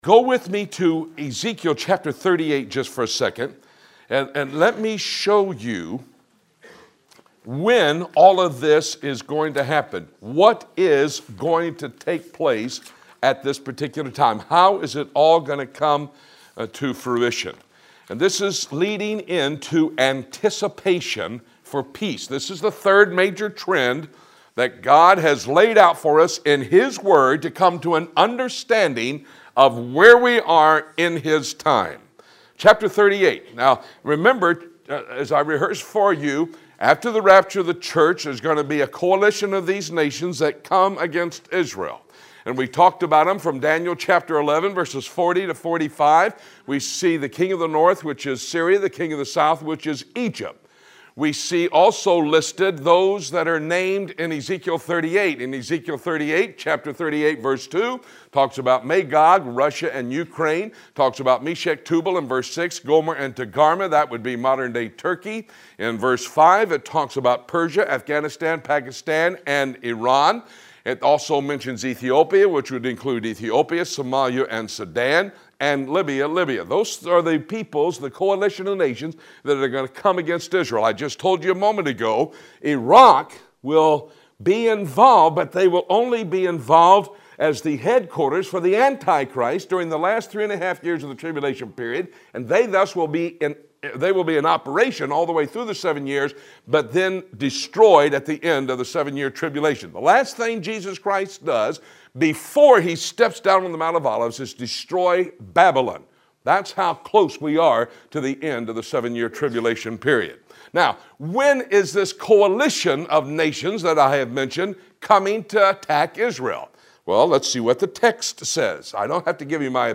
Listen to the audio of this lesson